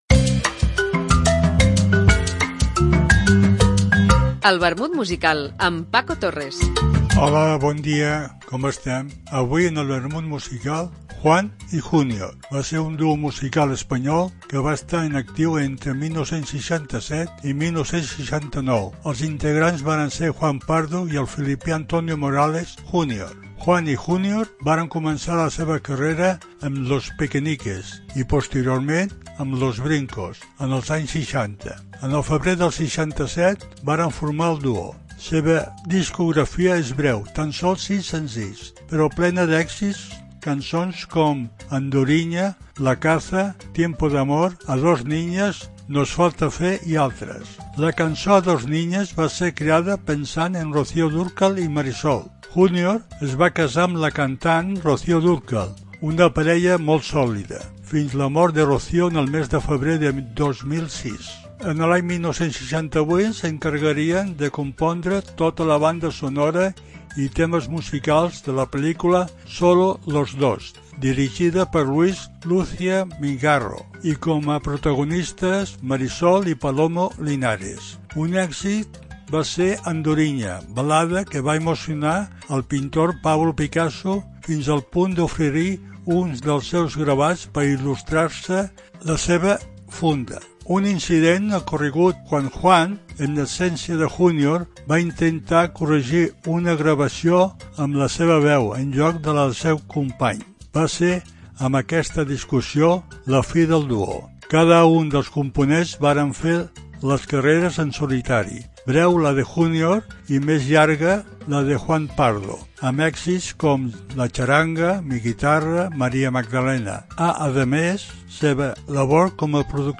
trios...etc. Una apunts biogràfics acompanyats per una cançó.